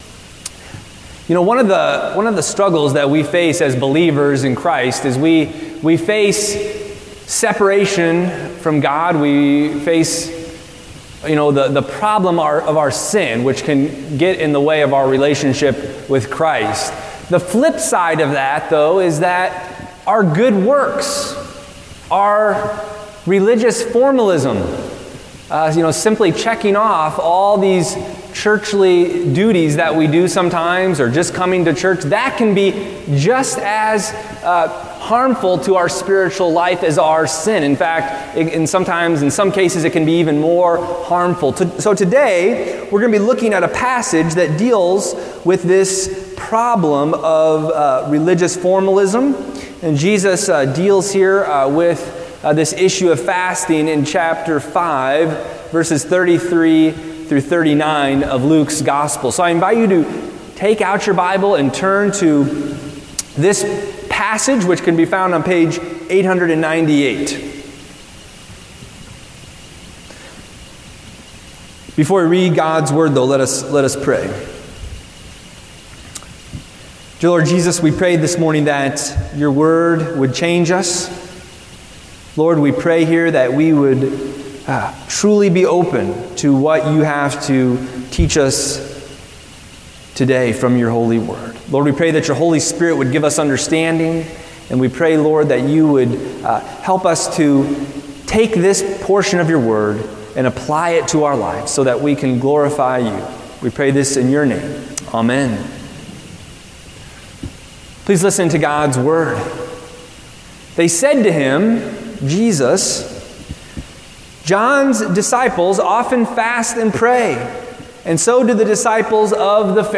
Service Type: Lent